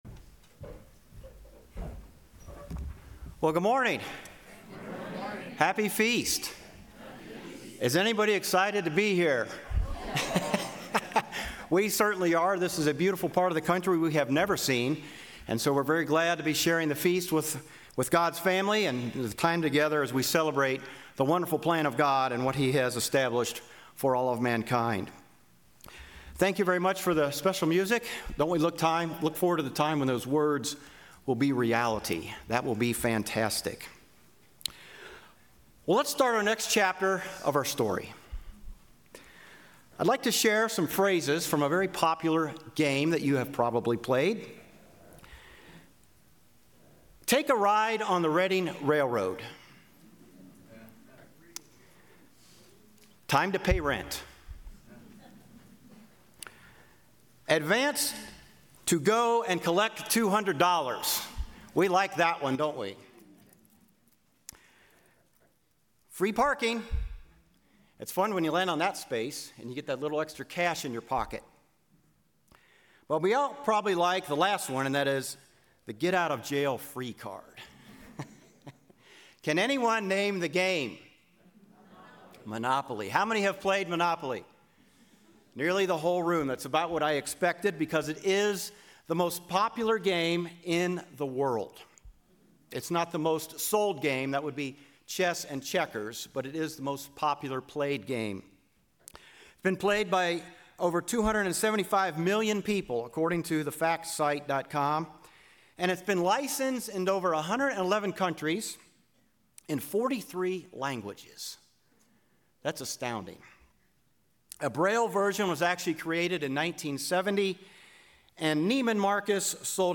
Split Sermon 2 - Day 2 - Feast of Tabernacles - Klamath Falls, Oregon
This sermon was given at the Klamath Falls, Oregon 2024 Feast site.